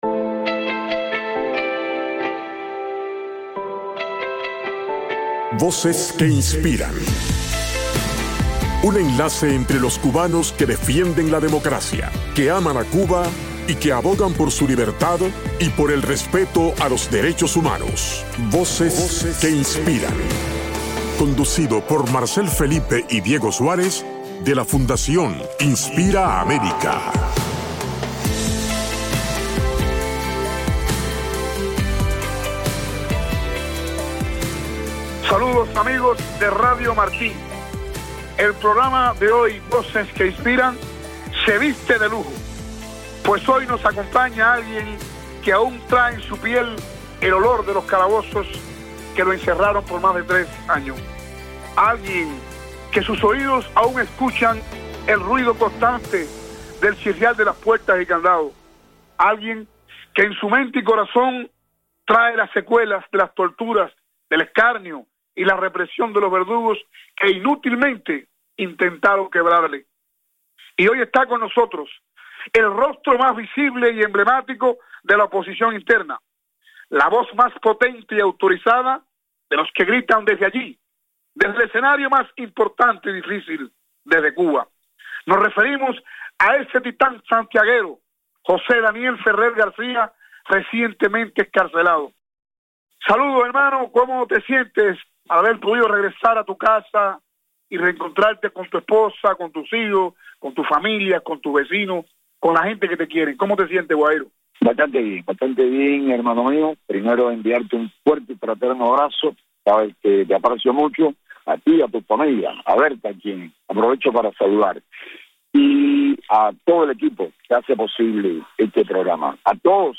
Entrevista con José Daniel Ferrer